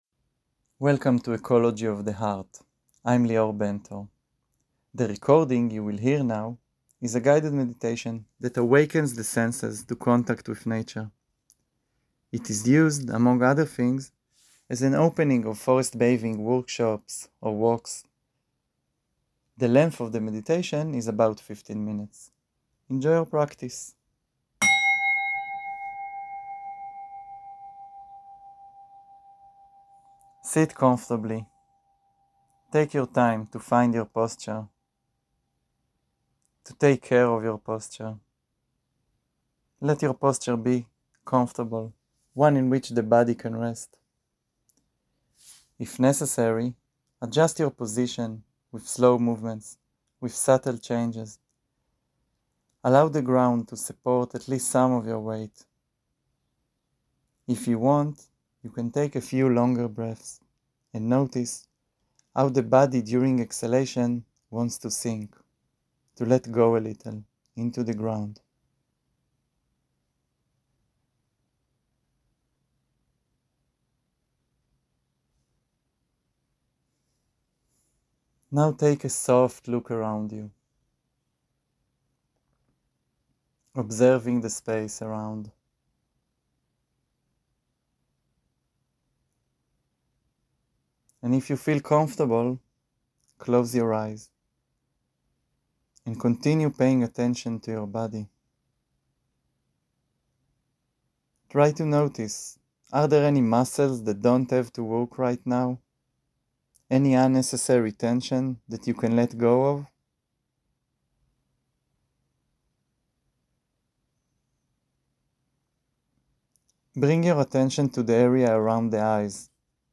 הנחיה קולית לתרגול מדיטציה - גני יהושע